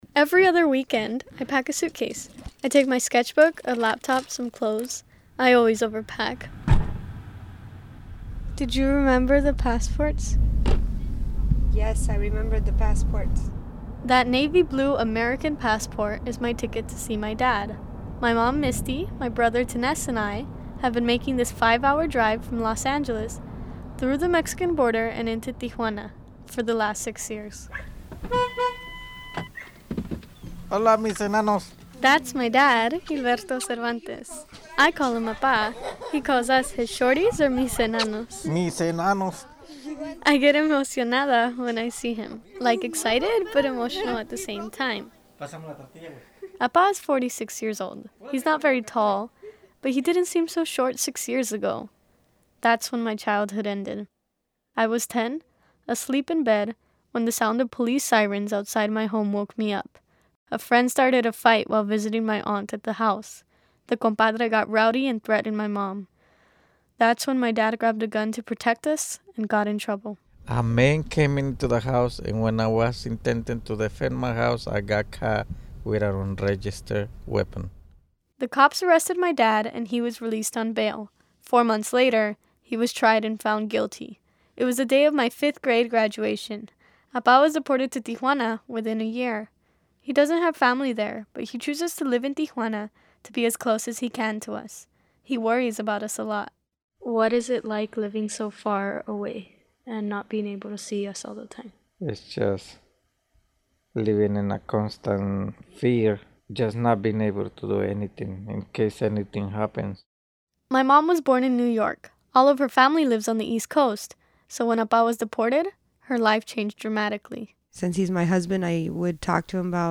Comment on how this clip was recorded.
Produced by Youth Radio in collaboration with Boyle Heights Beat.